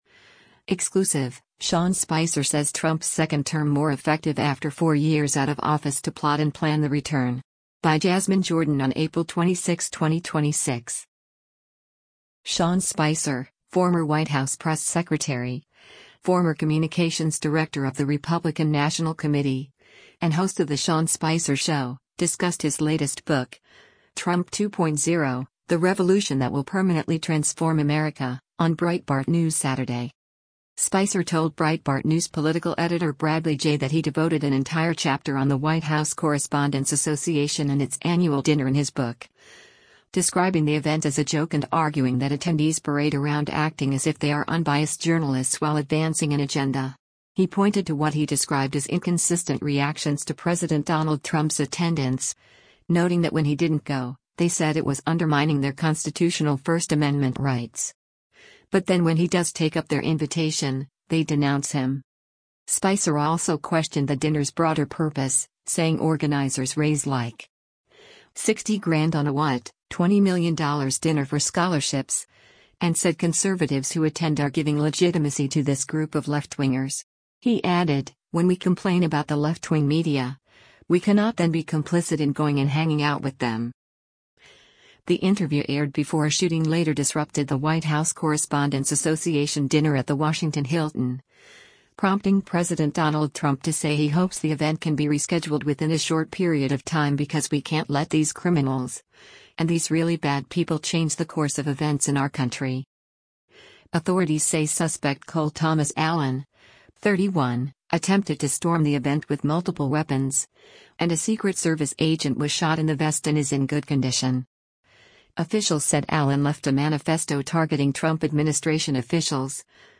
The interview aired before a shooting later disrupted the White House Correspondents’ Association Dinner at the Washington Hilton, prompting President Donald Trump to say he hopes the event can be rescheduled “within a short period of time” because “we can’t let these criminals, and these really bad people change the course of events in our country.”